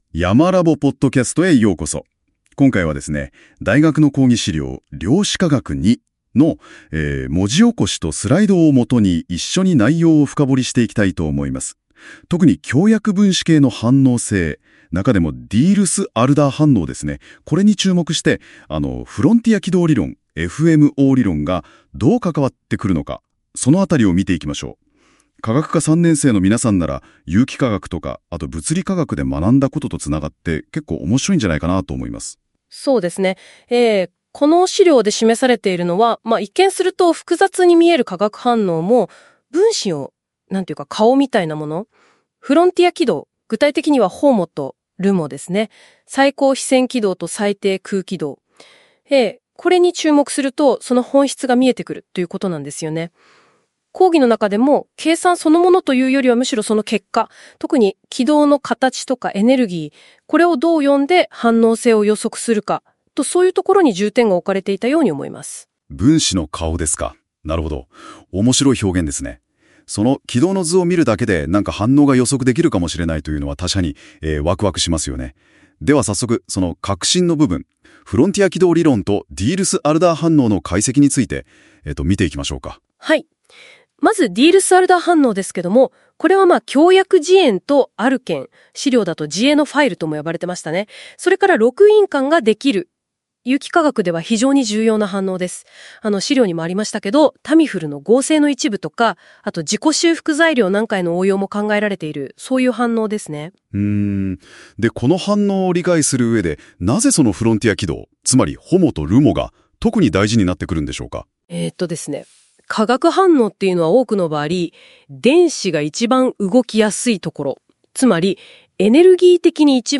量子化学２の「音声録音 」と「スライド」をもとに、講義の主要なトピックについて「２人のＡＩホストがおしゃべりする」というポッドキャスト風の音声概要を作りました。
Audio Channels: 1 (mono)